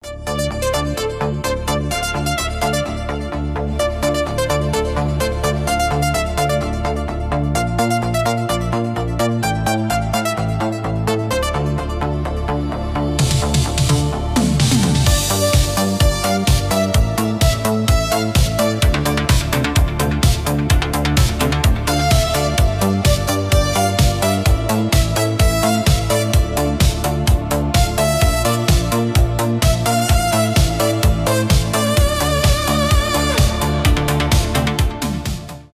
танцевальные
без слов
synth pop , диско